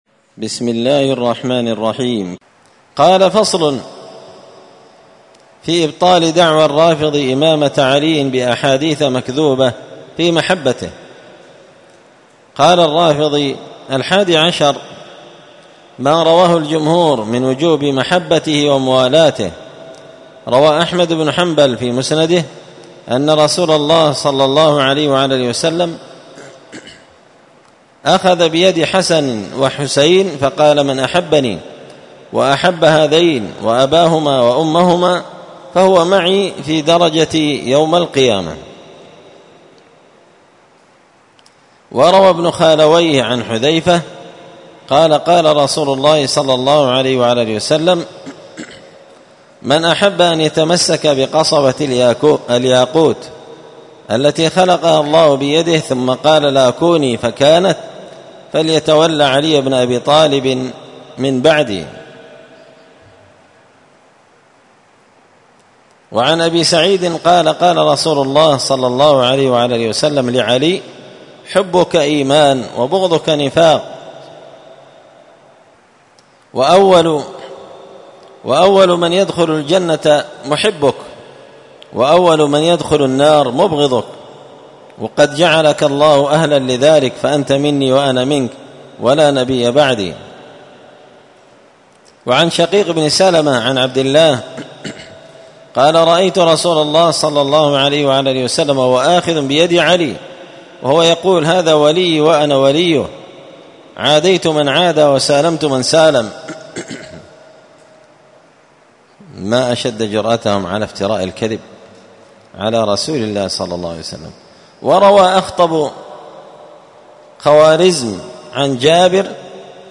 الأربعاء 28 صفر 1445 هــــ | الدروس، دروس الردود، مختصر منهاج السنة النبوية لشيخ الإسلام ابن تيمية | شارك بتعليقك | 86 المشاهدات